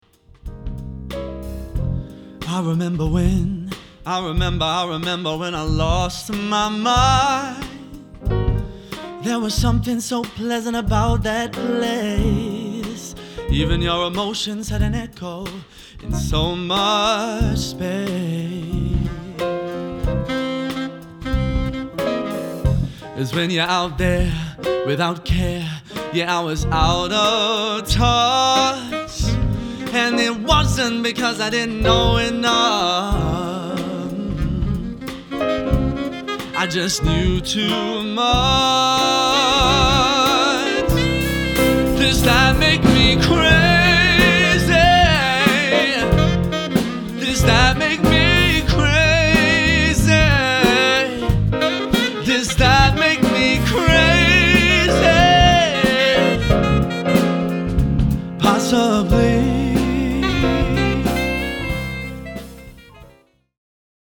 1. Slick, stylish duo of male vocals and guitar
2. Laidback arrangements of soul, jazz and chart hits
1. soul, chart & rock ‘n’ roll